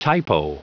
Prononciation du mot typo en anglais (fichier audio)
Prononciation du mot : typo